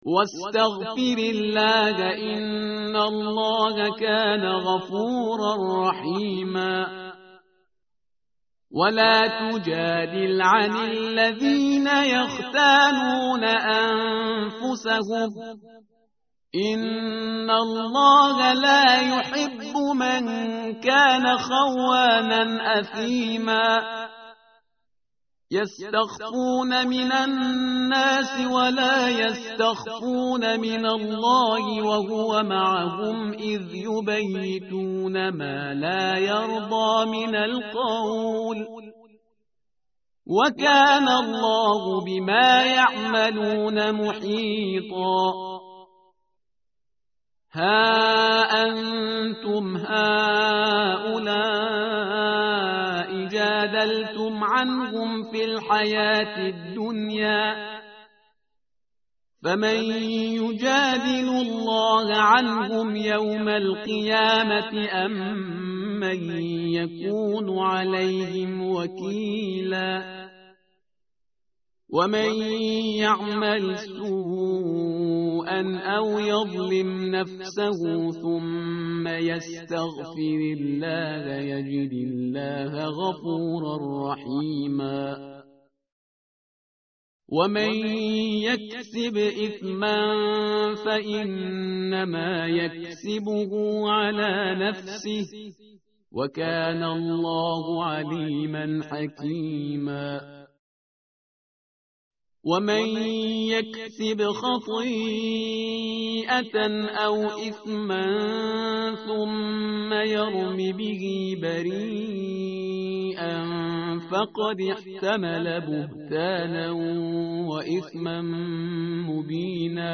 متن قرآن همراه باتلاوت قرآن و ترجمه
tartil_parhizgar_page_096.mp3